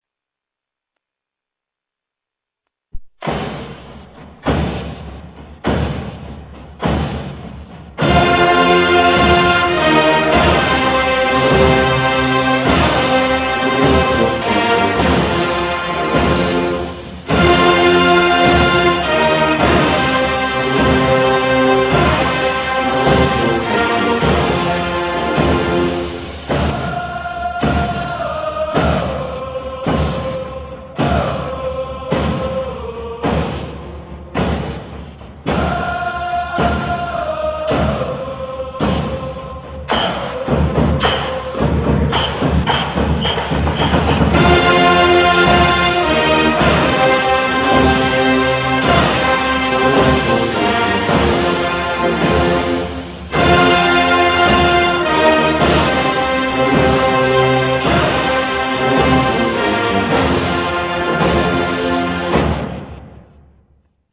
warchant.ram